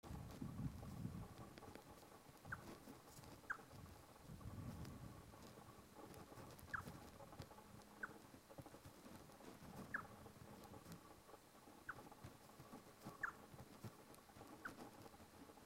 Rhinocrypta lanceolata
Nome em Inglês: Crested Gallito
Localidade ou área protegida: Parque Nacional Sierra de las Quijadas
Condição: Selvagem
Certeza: Gravado Vocal
Gallito-copeton-San-Luis.mp3